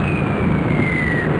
snd_11031_bombWhistle.wav